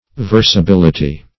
Search Result for " versability" : The Collaborative International Dictionary of English v.0.48: Versability \Ver`sa*bil"i*ty\, n. The quality or state of being versable.